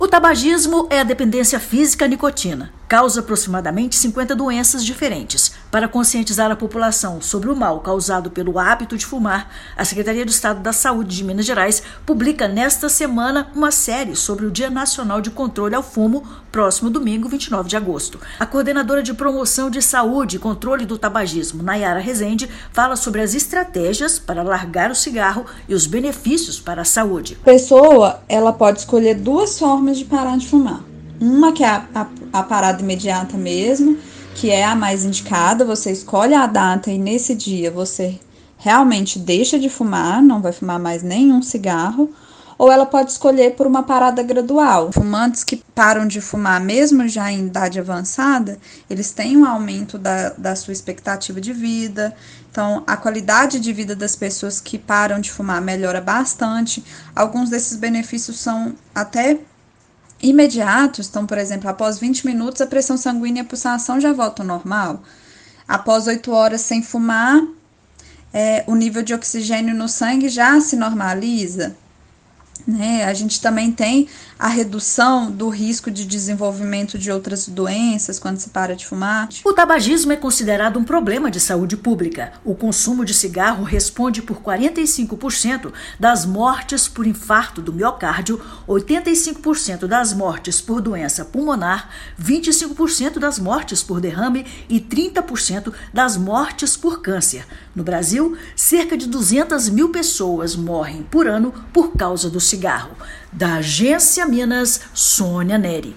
A Secretaria de Estado de Saúde de Minas Gerais (SES-MG) lança campanha de conscientização de combate ao fumo. Ouça a matéria de rádio.